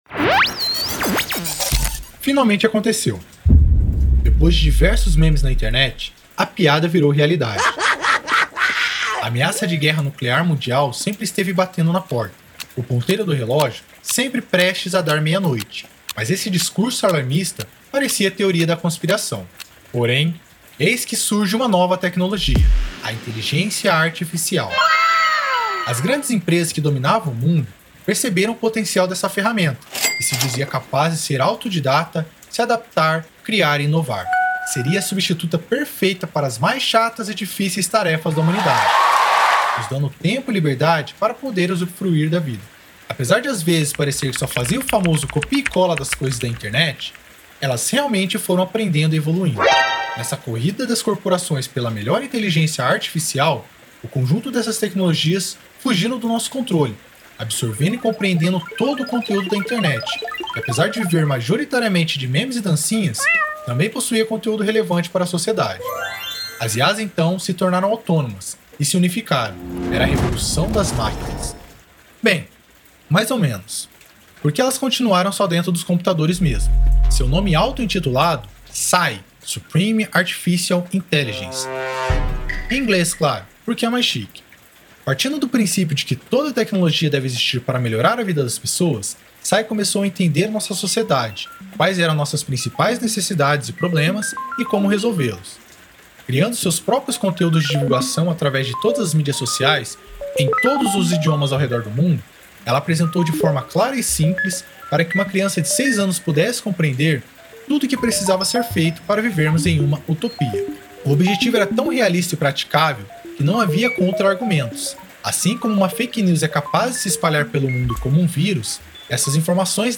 Ouça no áudio-drama com efeitos sonoros o começo da tragédia e descubra como o mundo se tornou esse caos.